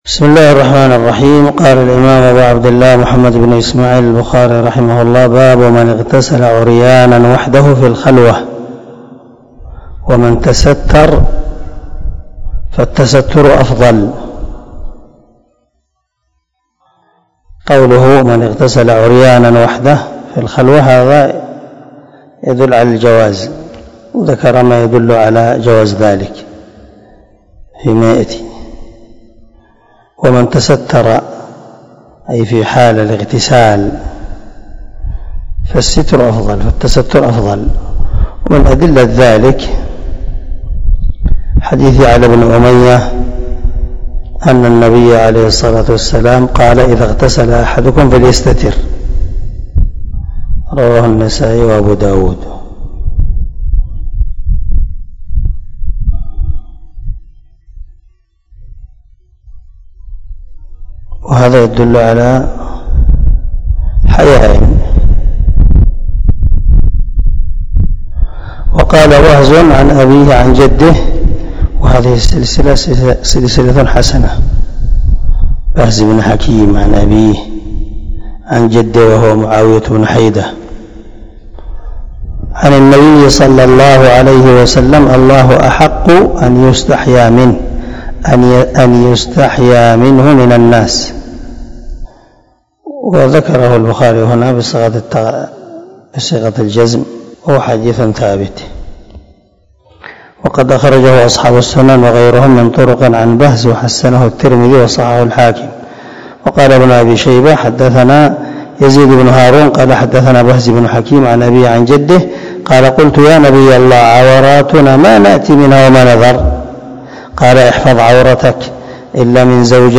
226الدرس 19 من شرح كتاب الغسل حديث رقم ( 278 ) من صحيح البخاري